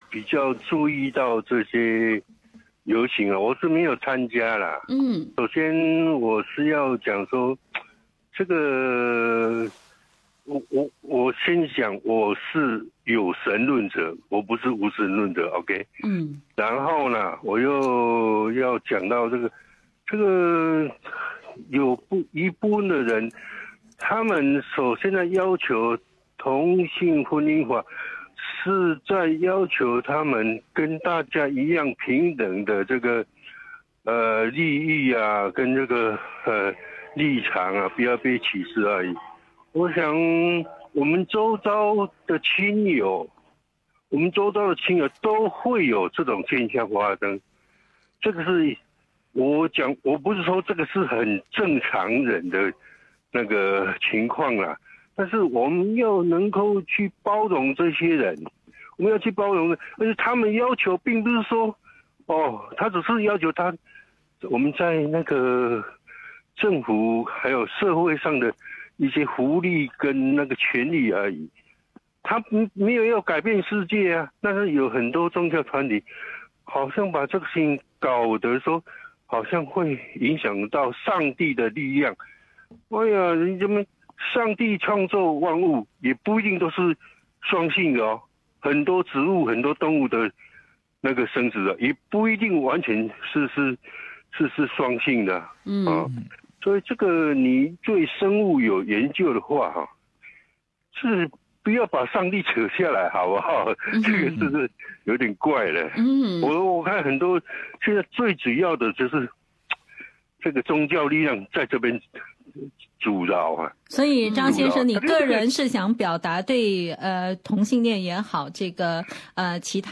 在本期节目中，许多听众展开了热烈的讨论，在网络上也有许多不同的声音，以下为一些听众网友的看法：